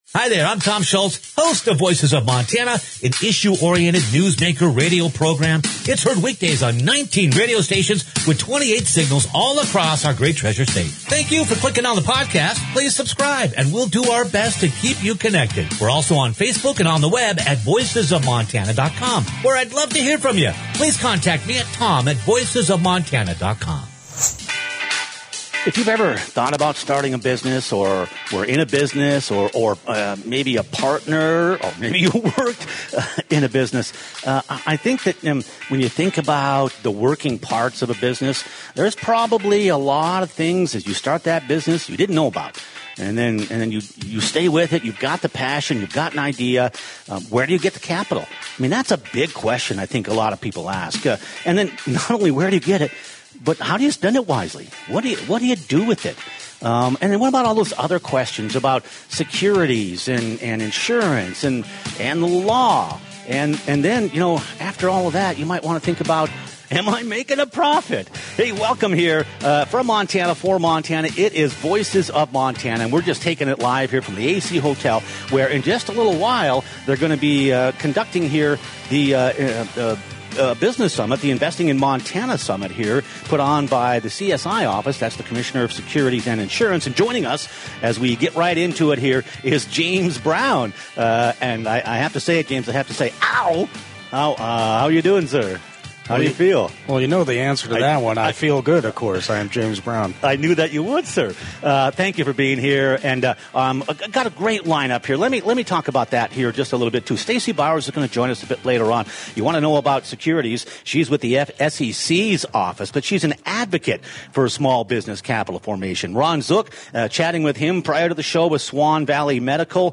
LIVE from the CSI's 2025 Investing in Montana Summit, Commissioner James Brown co-hosted this show exploring the investment landscape for businesses in Montana.